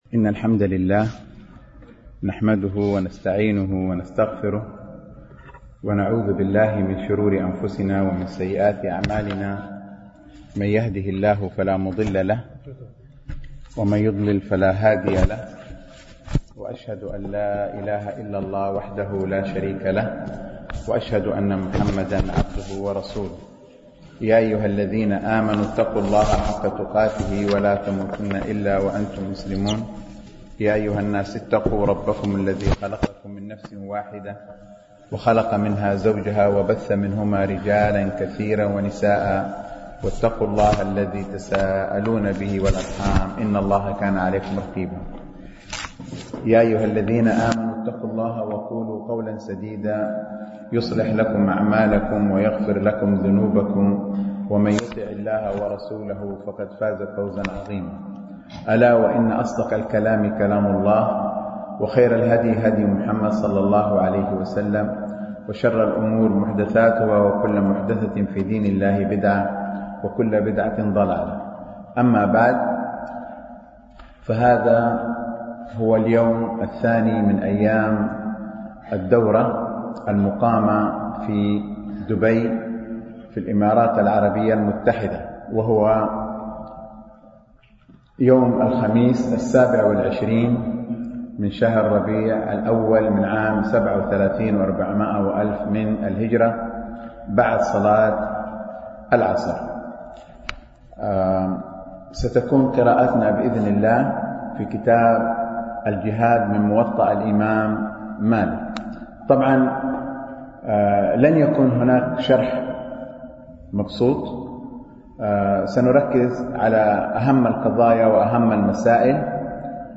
دروس مسجد عائشة (برعاية مركز رياض الصالحين ـ بدبي)